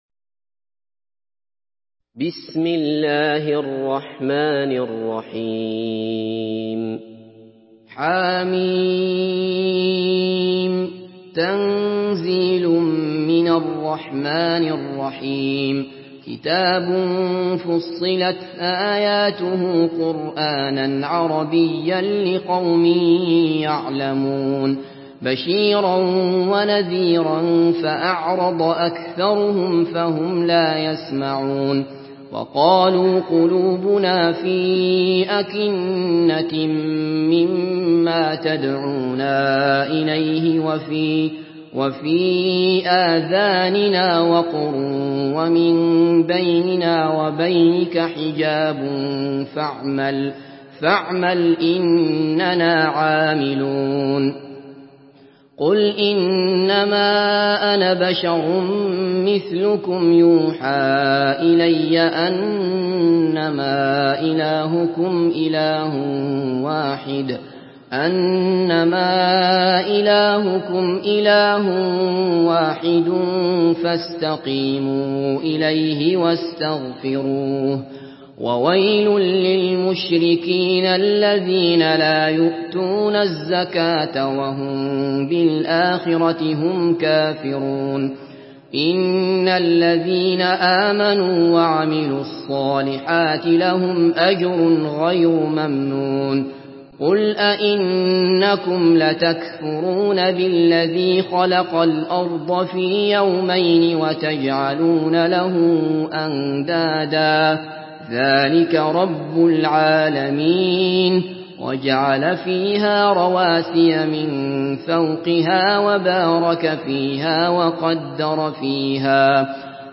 Surah Fussilat MP3 in the Voice of Abdullah Basfar in Hafs Narration
Murattal Hafs An Asim